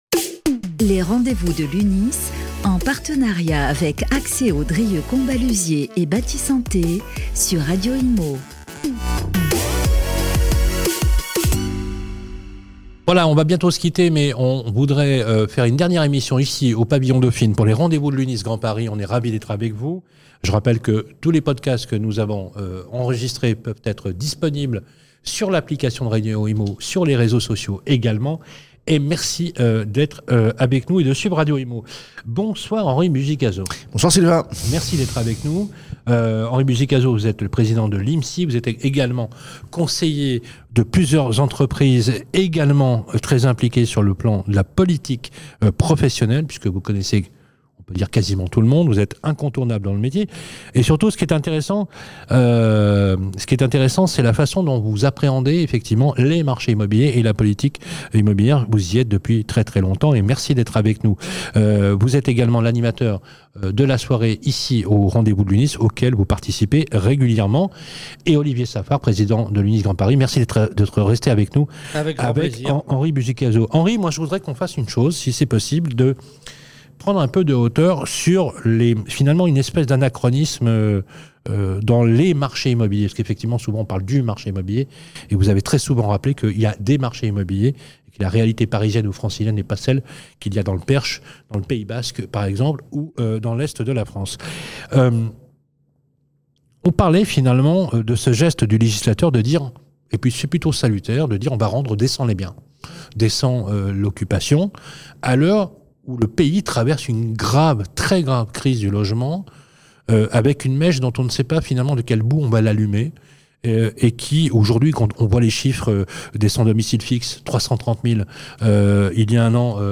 Conférence - Conseiller et vendre à l'ère du numérique